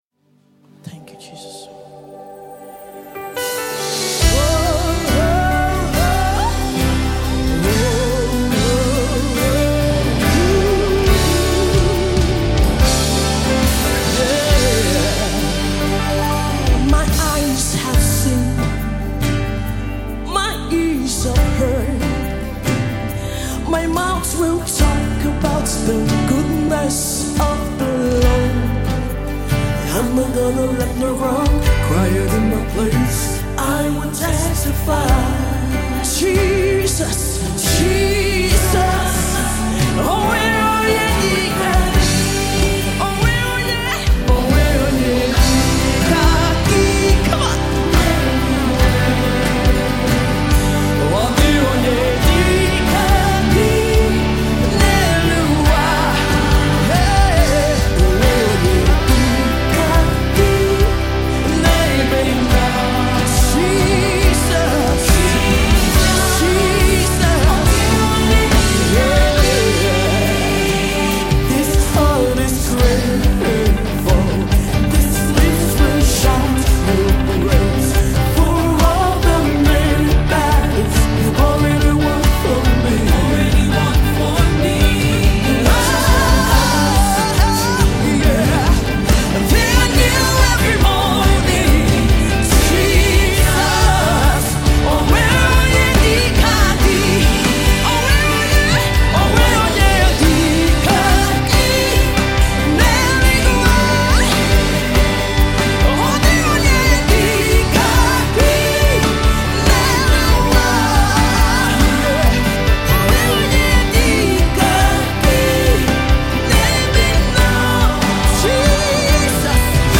Gospel
a heartfelt song of Thanksgiving.
Recorded Live in London.